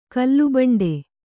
♪ kallubaṇḍe